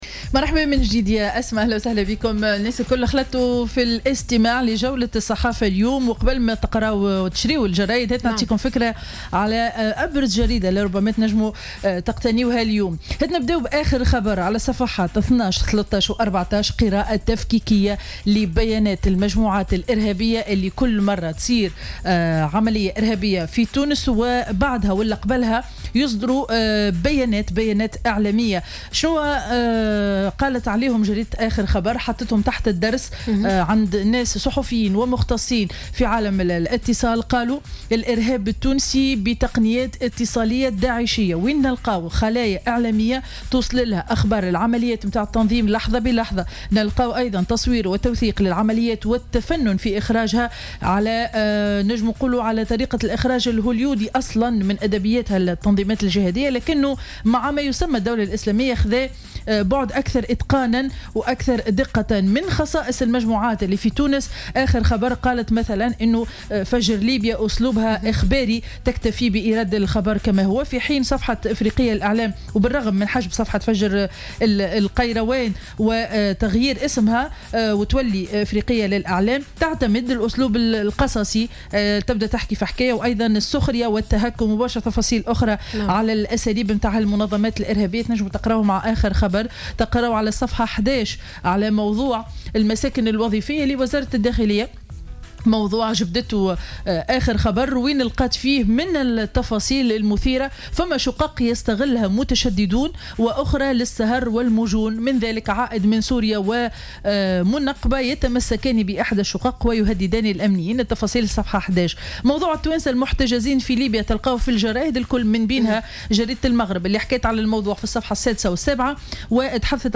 Revue de presse du 19 mai 2015